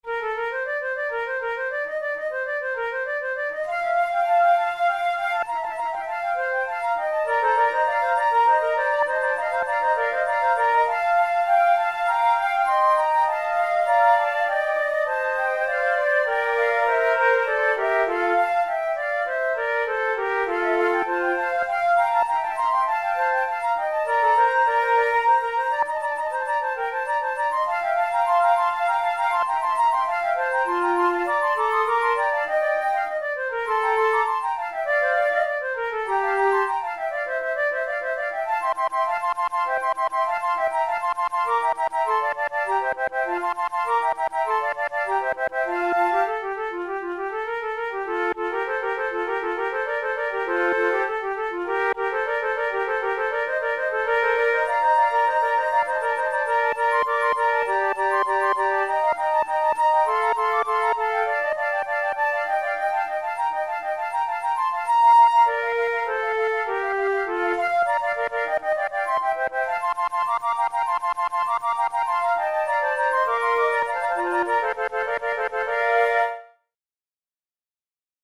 InstrumentationFlute trio
KeyB-flat major
Time signature4/4
Tempo100 BPM
Baroque, Sonatas, Written for Flute